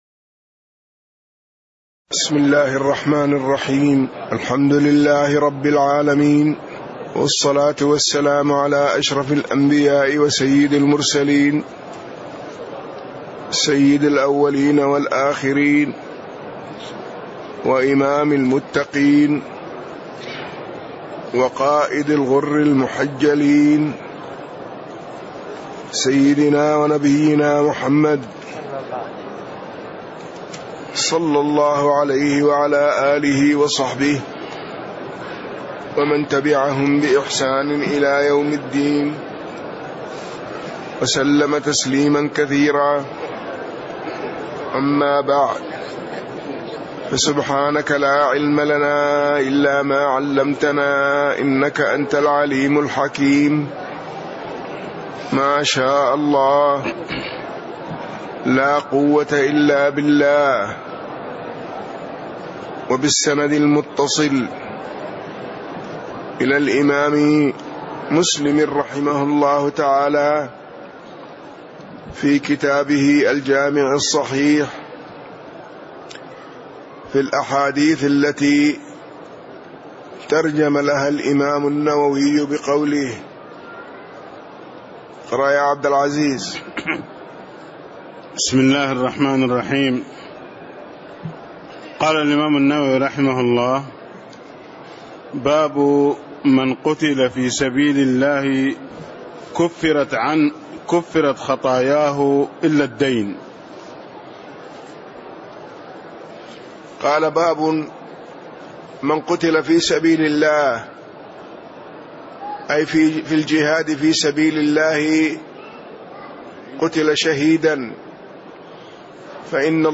تاريخ النشر ٢٧ ربيع الأول ١٤٣٦ هـ المكان: المسجد النبوي الشيخ